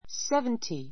seventy 小 A1 sévnti セ ヴ ンティ 名詞 複 seventies sévntiz セ ヴ ンティ ズ ❶ 70 ; 70歳 さい Open your books to page seventy.